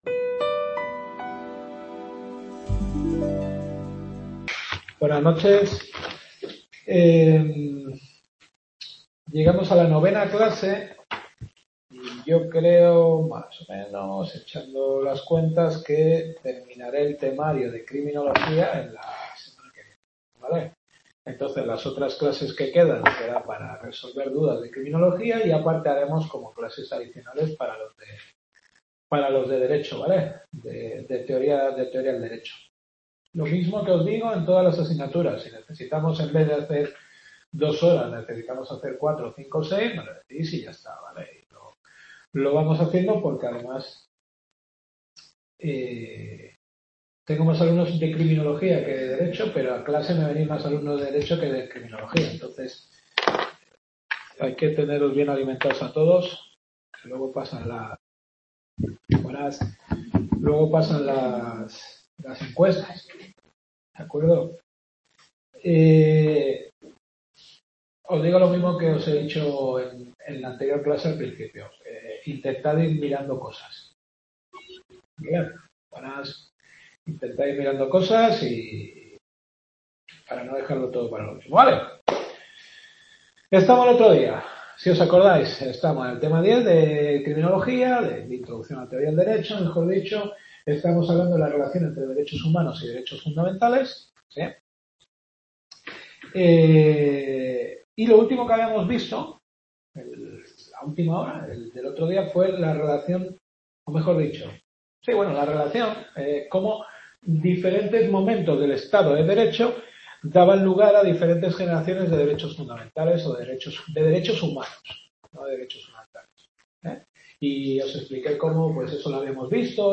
Teoría del Derecho-Introducción a la Teoría del Derecho. Novena Clase.